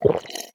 Minecraft Version Minecraft Version 1.21.5 Latest Release | Latest Snapshot 1.21.5 / assets / minecraft / sounds / mob / wandering_trader / drink_milk1.ogg Compare With Compare With Latest Release | Latest Snapshot
drink_milk1.ogg